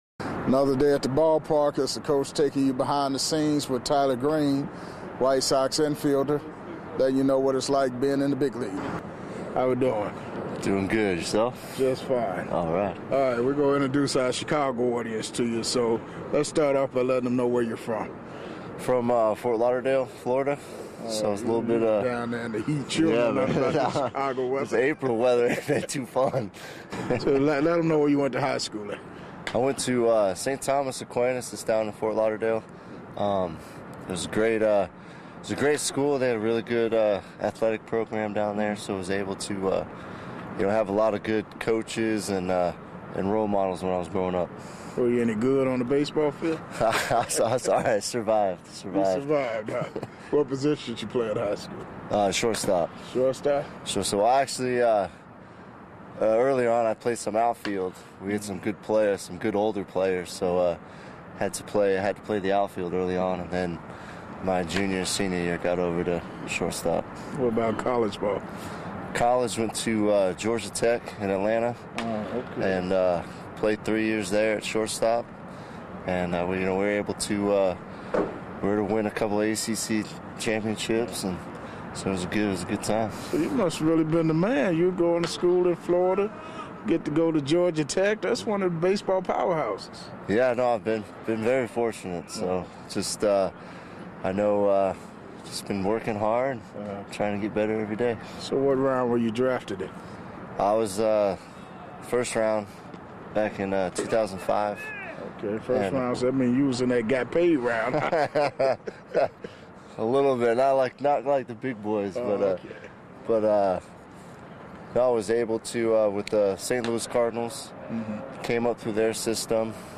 Taking you behind the scenes full uncut and unedited MLB interviews with past and present players